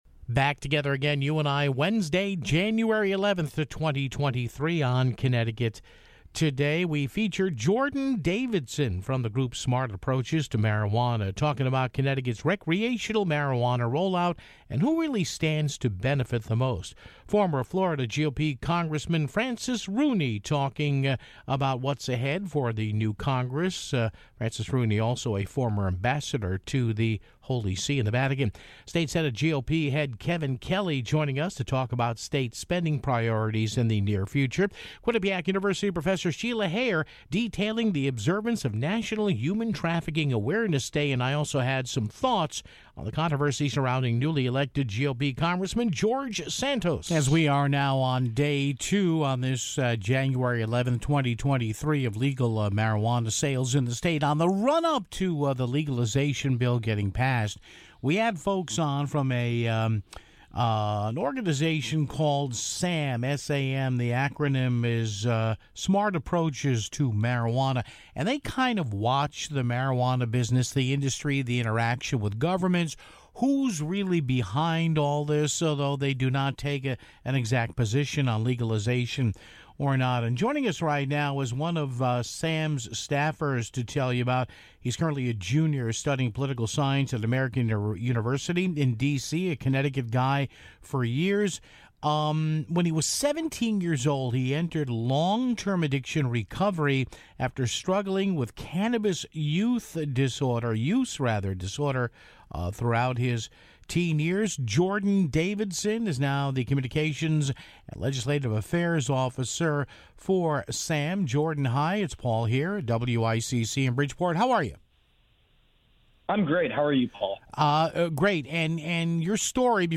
Former Florida GOP Congressman Francis Rooney talked about what's ahead for the new Congress (14:48). State Senate GOP Leader Kevin Kelly joined us to talk about state spending priorities for the near future (26:13).